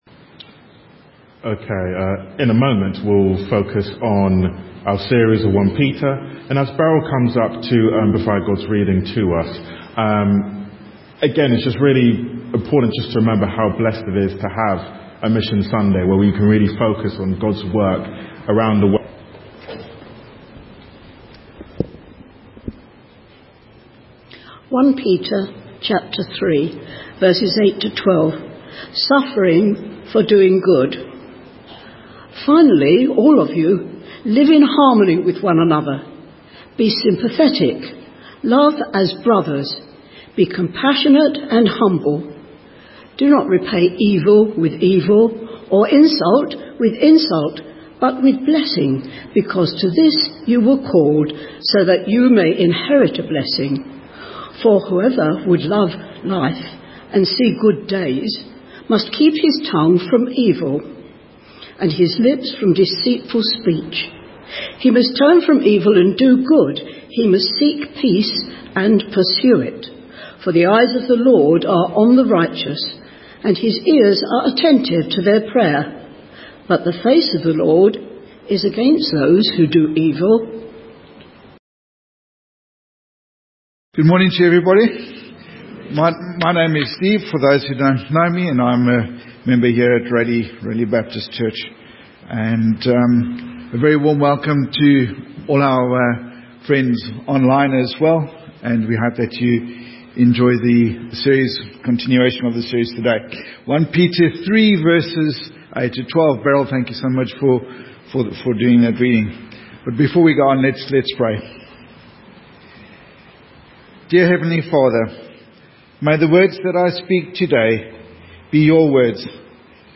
A message from the series "1 Peter."
From Series: "Sunday Morning - 10:30"